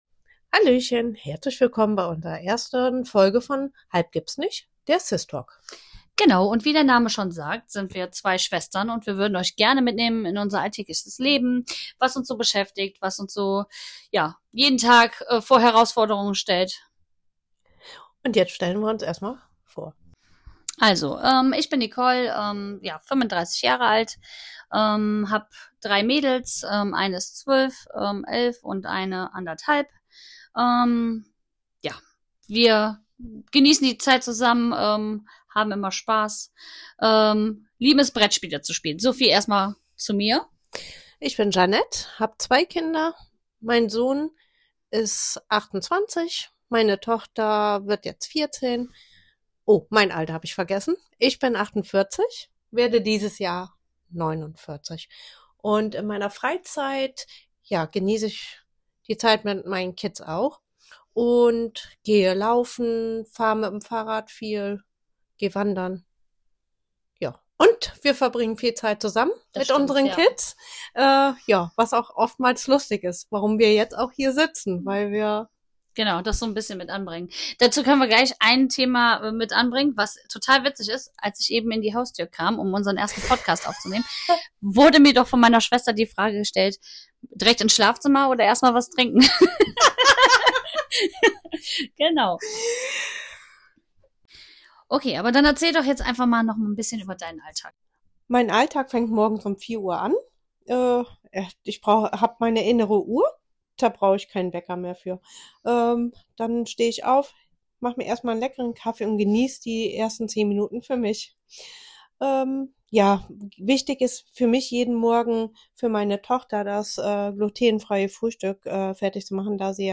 In jeder Folge nehmen wir euch mit in ehrliche, spontane Gespräche über Alltag, Entscheidungen, Peinlichkeiten und Träume – ohne Skript, aber mit viel Herz und Humo...